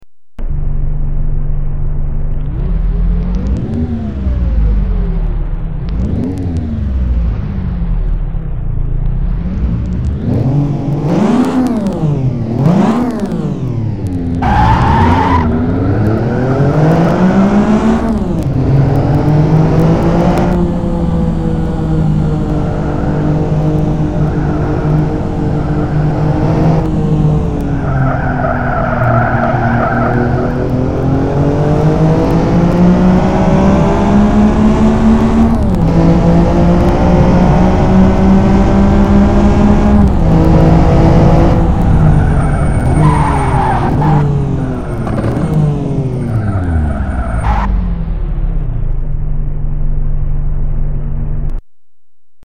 Samples taken from onboard Porsche footage. I know you may find it a bit too subtle, but don't forget that its a road car. Also the crackling and bad quality is due to my laptop's crappy soundcard, my desktop PC is not with me.
Hmm sounds pretty good!